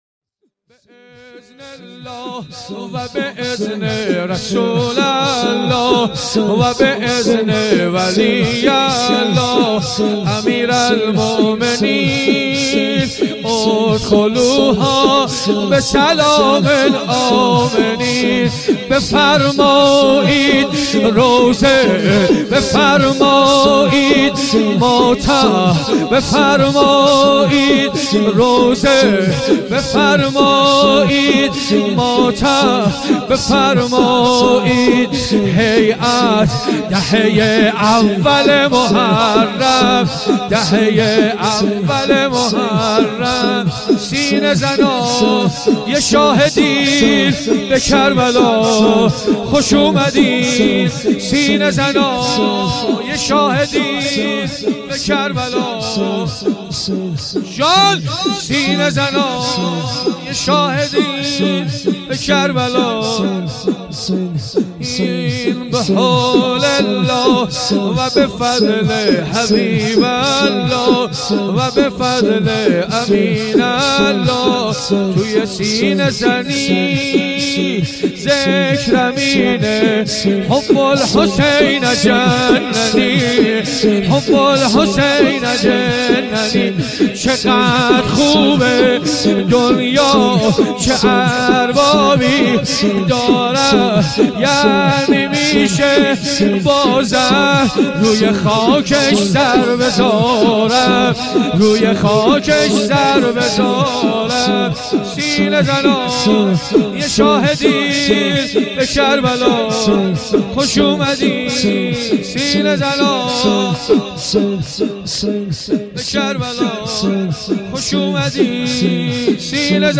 شور
دهه دوم محرم 1440 شب اول